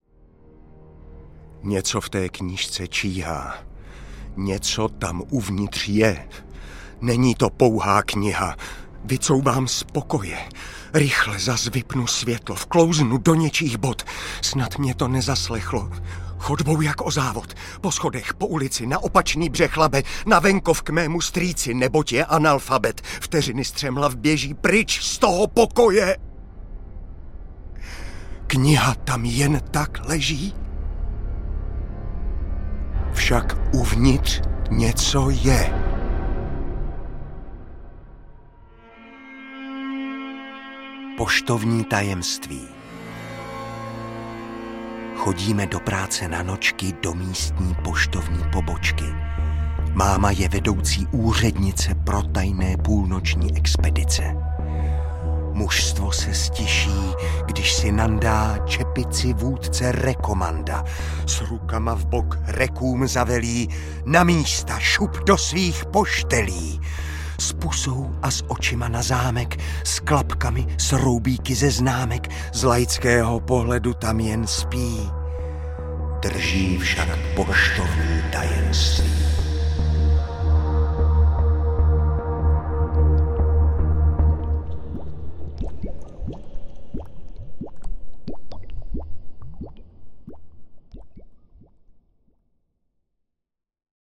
Tam uvnitř něco je audiokniha
Ukázka z knihy
Čte Karel Dobrý.
Vyrobilo studio Soundguru.
• InterpretKarel Dobrý